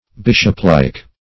Search Result for " bishoplike" : The Collaborative International Dictionary of English v.0.48: Bishoplike \Bish"op*like`\, a. Resembling a bishop; belonging to a bishop.